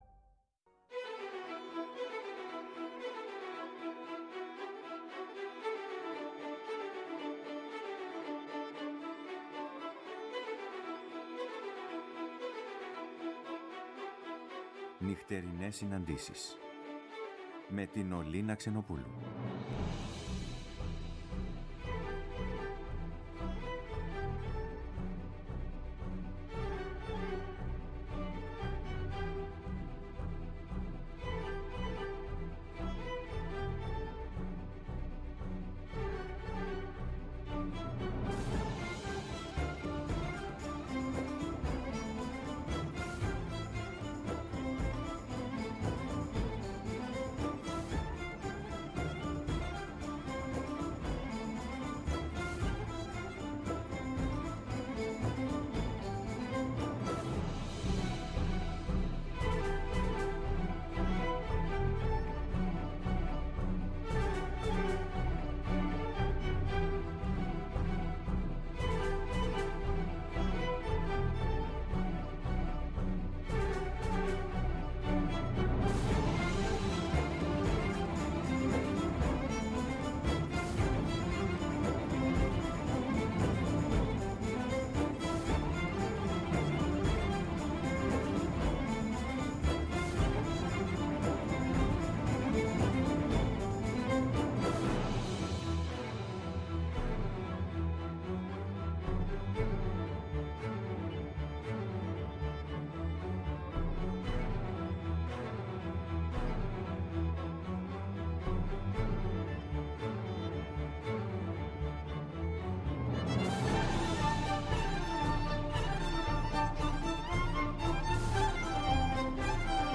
Η μουσική συνοδεύει, εκφράζοντας το «ανείπωτο».